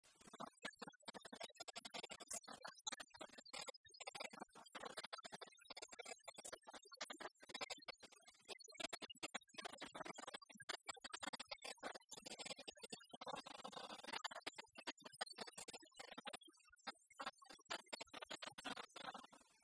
Venansault ( Plus d'informations sur Wikipedia ) Vendée
Genre énumérative
Pièce musicale inédite